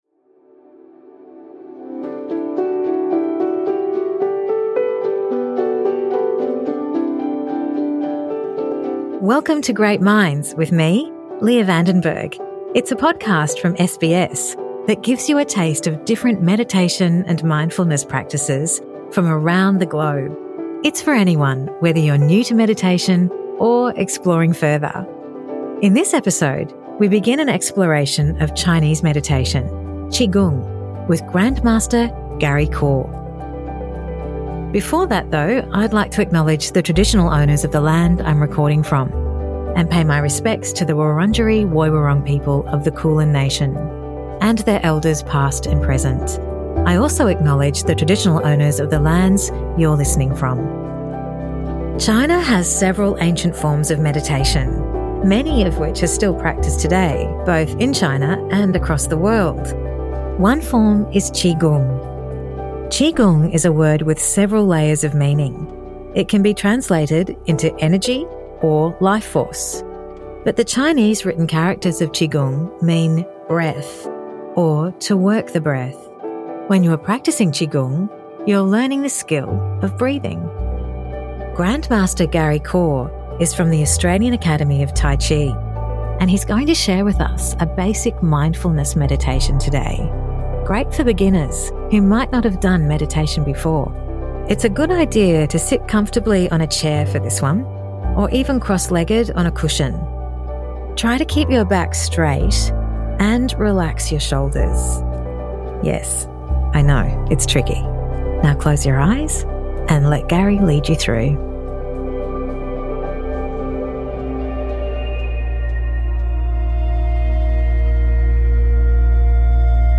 This episode is a basic mindfulness meditation that is great for beginners. Sit comfortably on a chair or cushion to start. Try to keep your back straight and relax your shoulders.